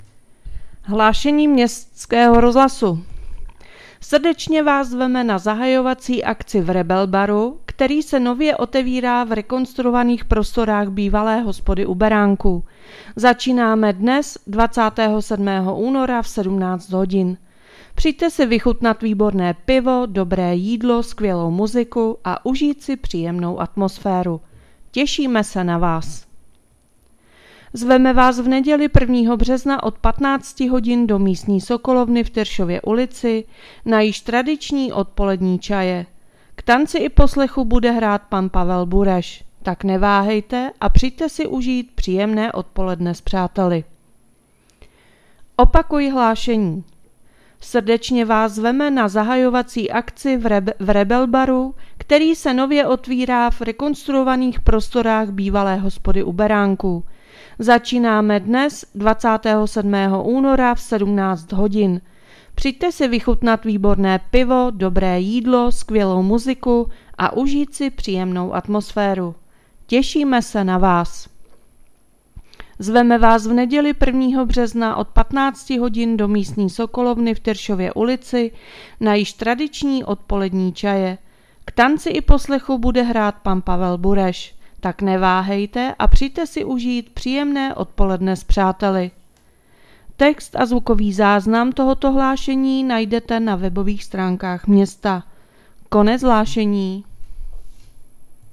Hlášení městského rozhlasu 27.2.2026